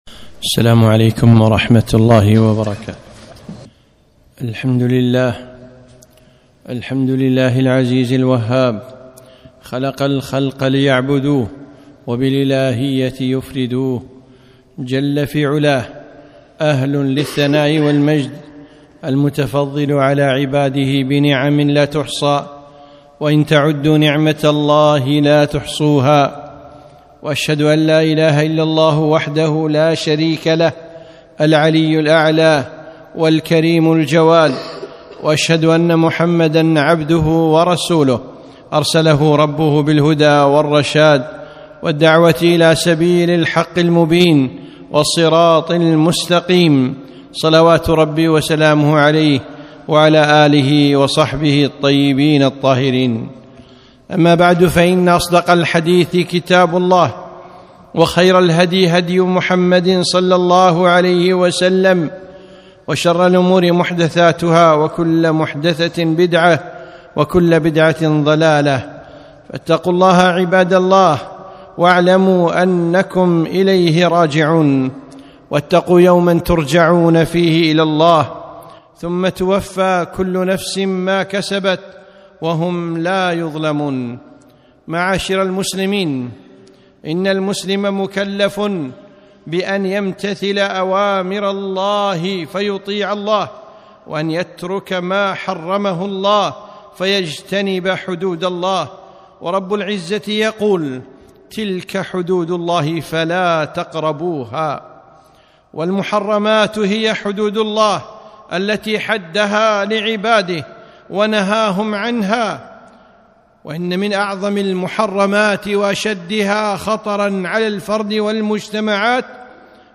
خطبة - خطر الربا